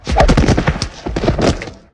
bodyslam.ogg